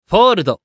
男性
☆★☆★システム音声☆★☆★